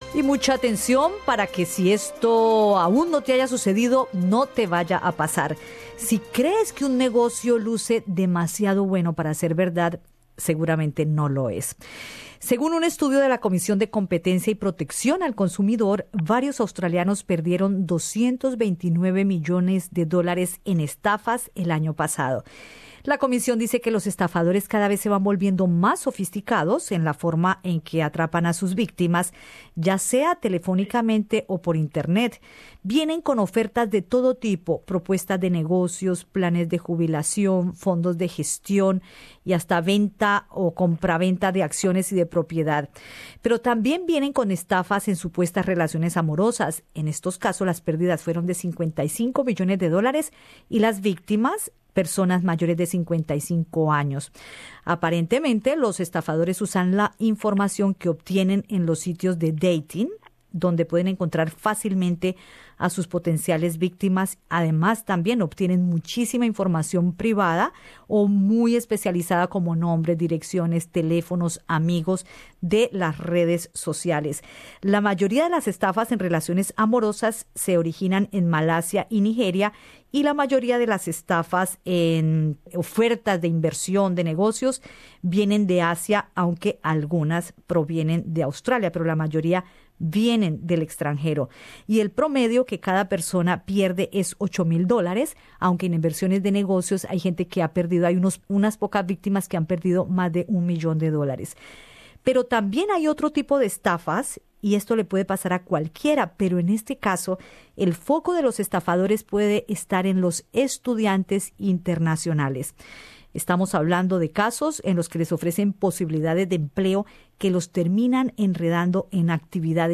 La cónsul de Colombia en Sydney, Nancy Benítez, advierte sobre el riesgo que los estudiantes internacionales pueden correr al aceptar ofertas laborales por Internet, en las que les ofrecen jugosas ganancias, pero cuya actividad está ligada al lavado de activos. En conversación con Radio SBS, la funcionaria reveló que el consulado ya ha conocido casos en los que estudiantes han enviado a su empleador detalles de su pasaporte, visa y cuentas bancarias, antes de saber la labor por la cual los están contratando.